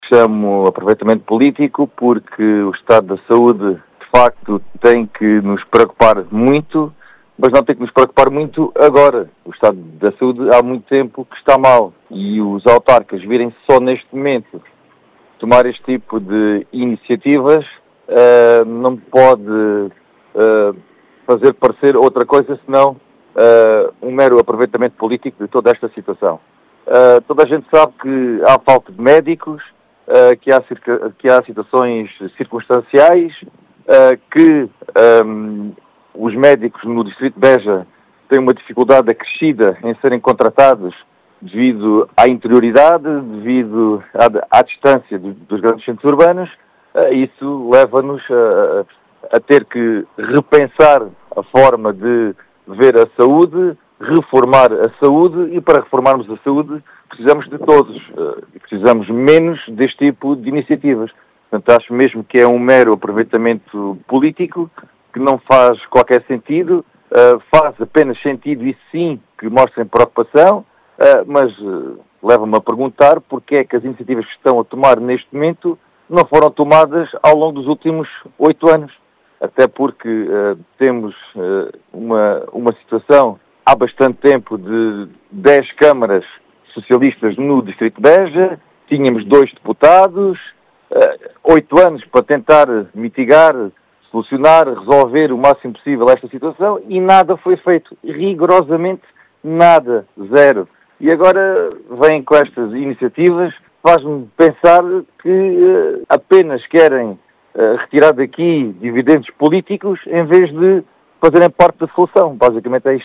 Em declarações à Rádio Vidigueira, o deputado da AD, fala em “mero aproveitamento politíco”, uma vez que o estado da saúde deve “preocupar muito mas não é só agora”.